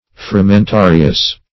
Search Result for " frumentarious" : The Collaborative International Dictionary of English v.0.48: Frumentarious \Fru`men*ta"ri*ous\, a. [L. frumentarius.] Of or pertaining to wheat or grain.